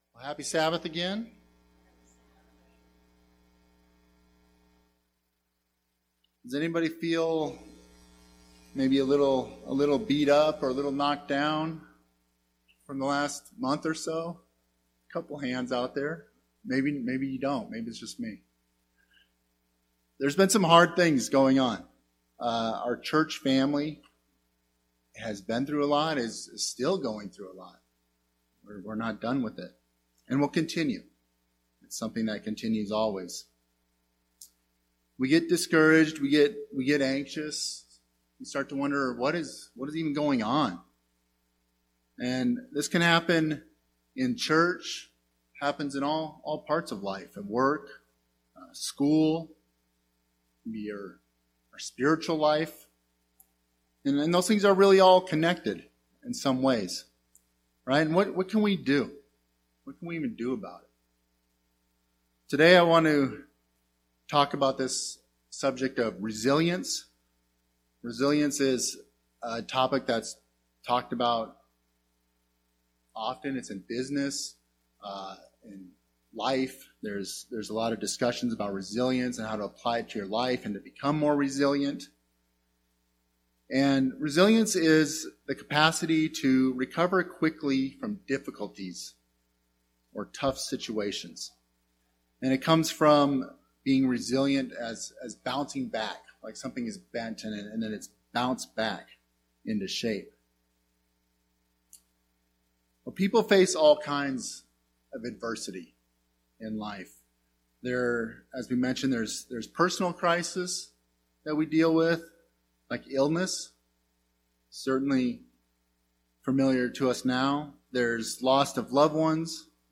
Given in Phoenix Northwest, AZ